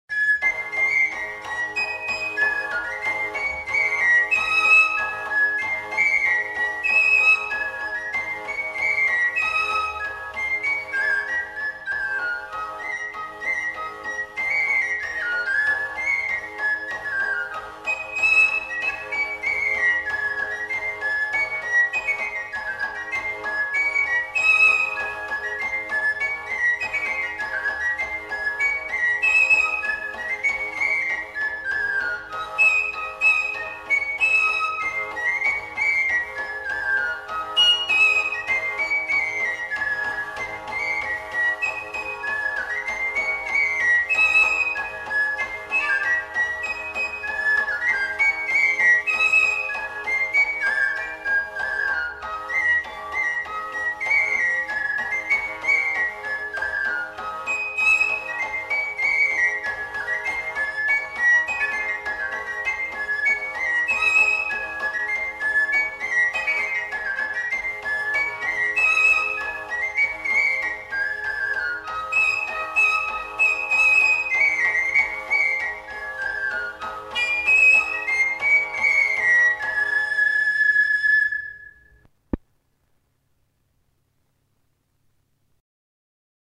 Rondeau
Aire culturelle : Béarn
Lieu : Bielle
Genre : morceau instrumental
Instrument de musique : flûte à trois trous ; tambourin à cordes
Danse : rondeau